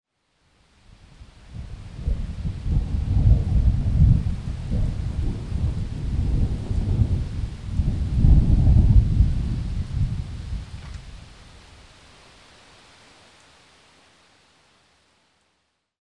rain_thunder03.mp3